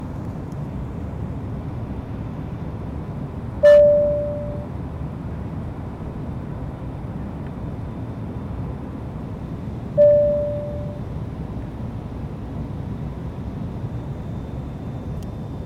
Airplane Ding 1.aiff